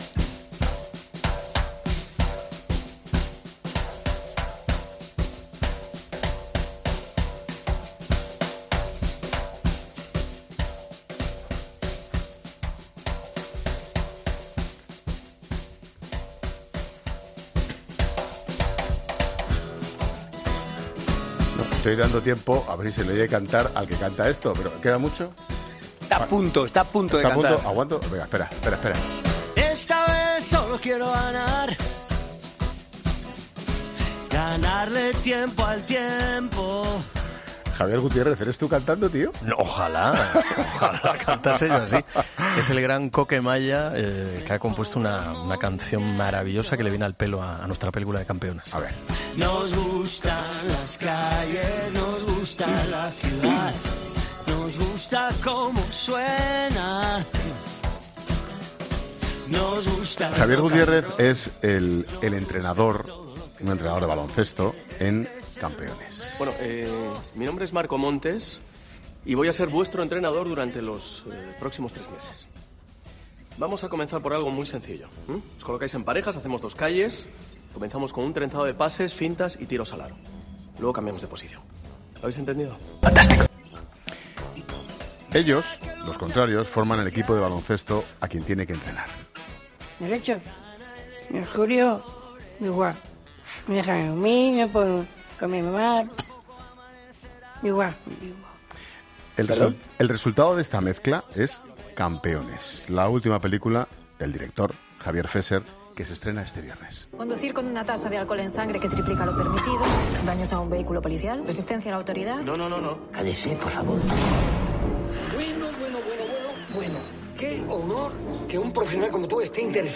Hablamos en 'La Tarde' con Javier Gutiérrez, actor, y con Javier Fesser, director de "Campeones", su nueva película que se estrena este viernes